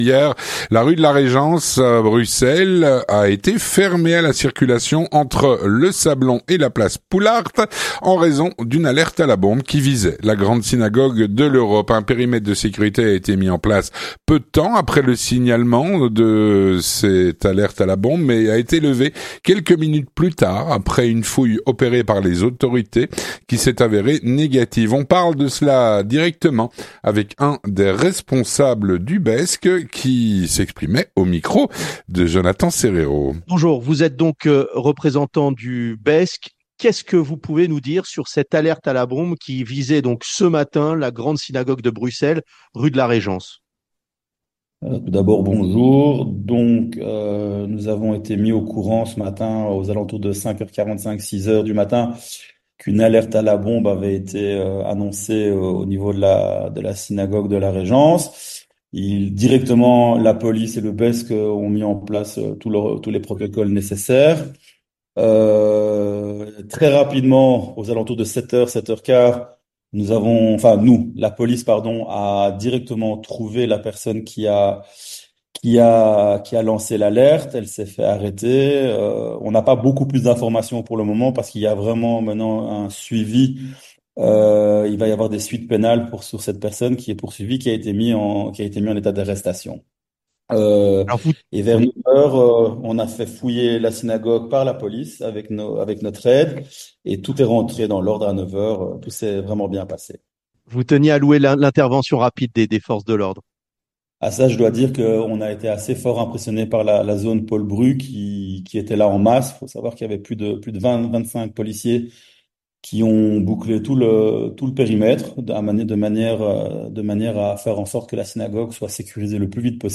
L'entretien du 18H - Alerte à la bombe à la Grande Synagogue de l'Europe, hier, à Bruxelles.
On en parle tout de suite avec un des responsables du BESC.